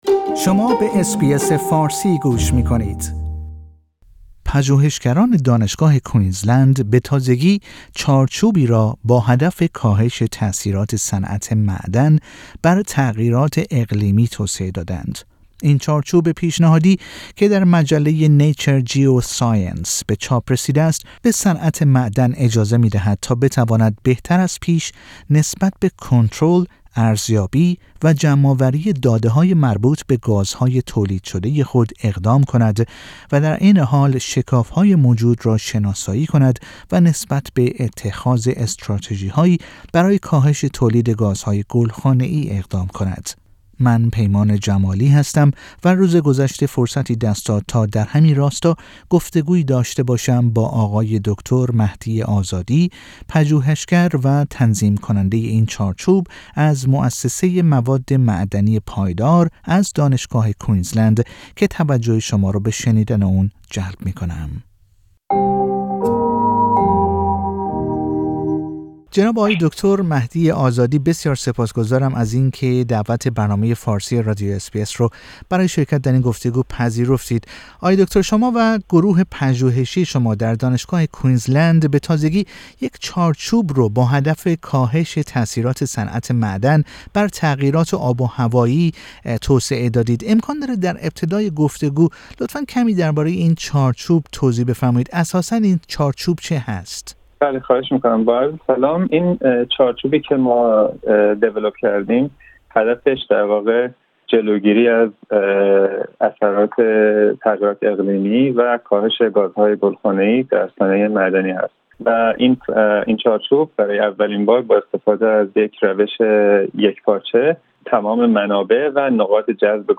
در گفتگو با رادویو اس بی اس فارسی درباره این چارچوب سخن می گوید.